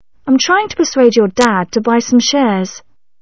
- نطق persuade هو /pər swād/.
- نطق some هو /sūm/.
- نطق shares هو /sherz/.
- الكلمات التي باللون الأحمر ستسمعها بوضوح.
- أما الكلمات أو المقطع التي باللون الرمادي فبالكاد ستسمعها.